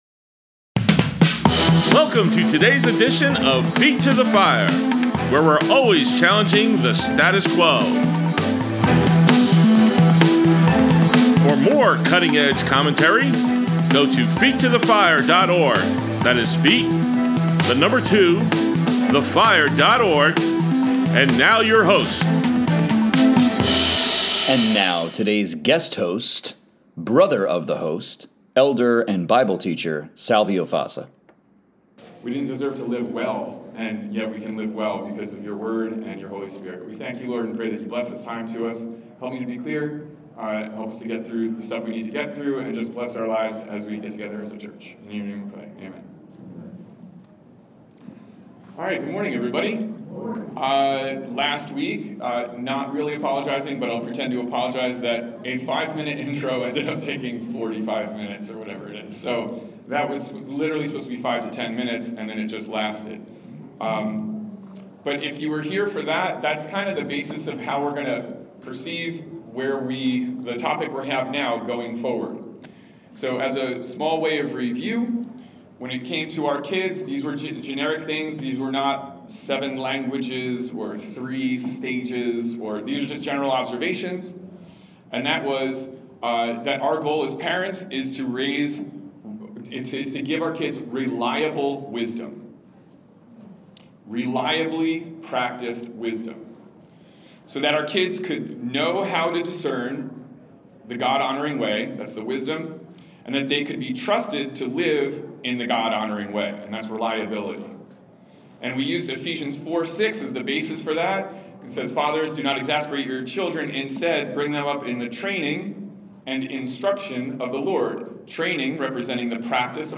Saturday Sermons: Raising a Godly Generation: Kids and Teenagers